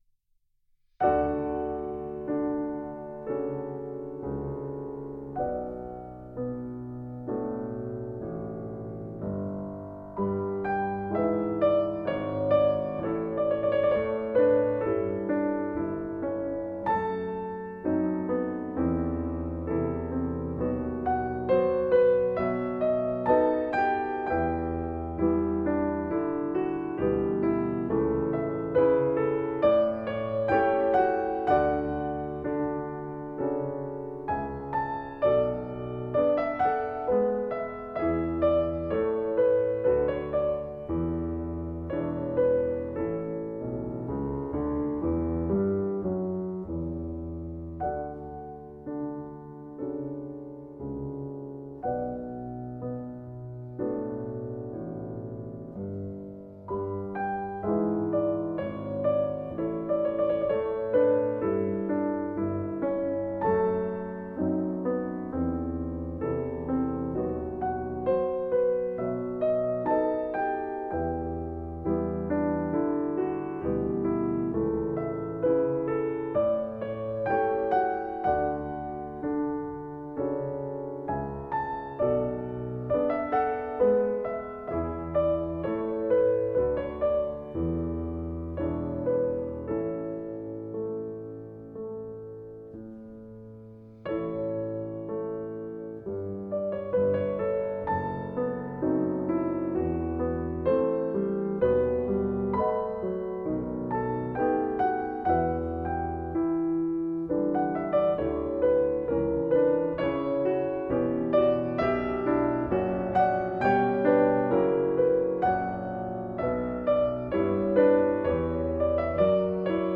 Emil Frey: Piano Transcription of the Air from Bach’s Third Suite for Orchestra BWV 1068.